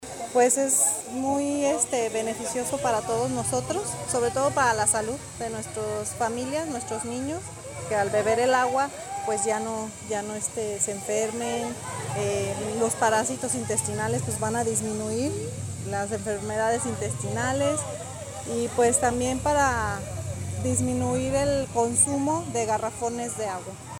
AudioBoletines
Lorena Alfaro, presidenta municipal